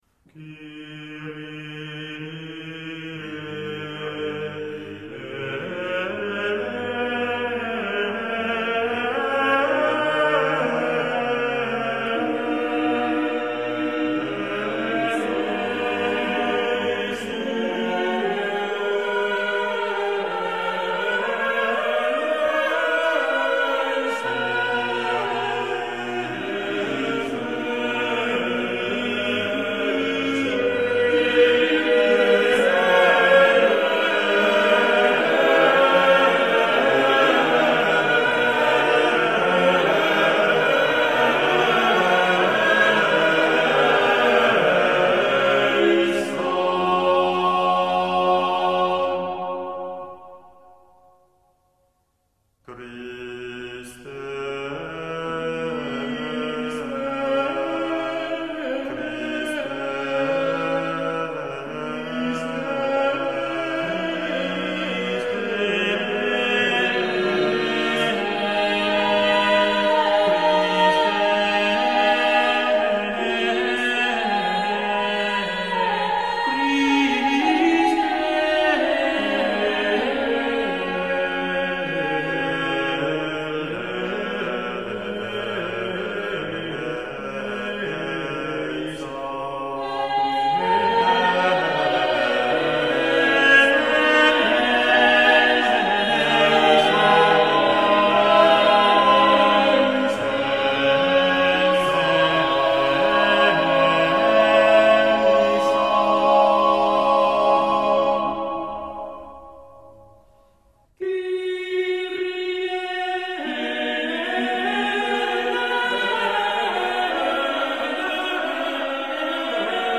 Paraphrase mass
Paraphrase mass ~1470 - ~1600 (Renaissance) Group: Mass A source tune, which could be either sacred or secular, is elaborated, usually by ornamentation but occasionally by compression. Usually in paraphrase masses the tune appears in any voice.